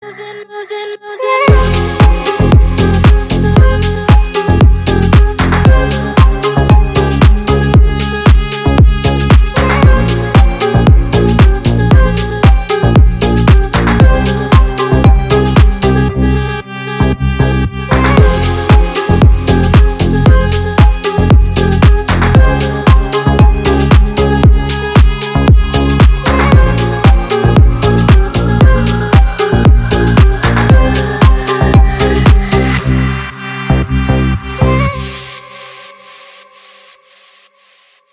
Спокойный рингтон 2024